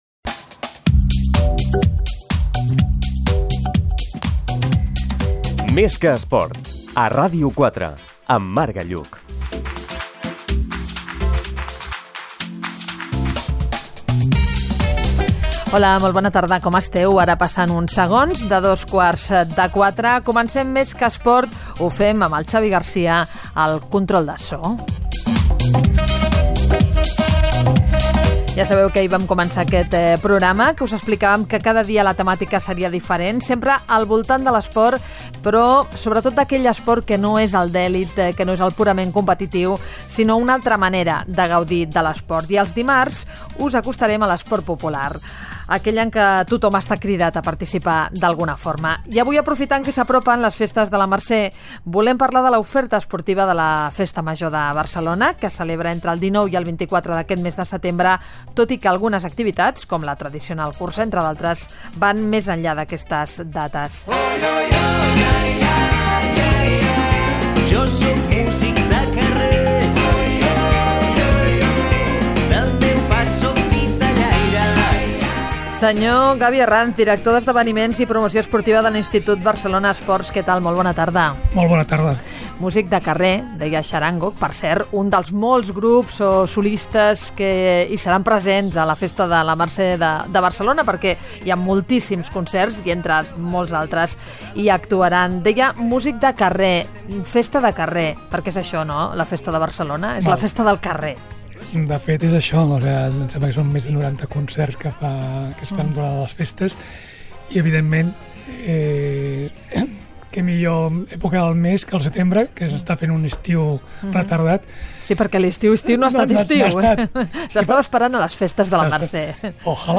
Careta del programa, hora i presentació de la segona edició.
Esportiu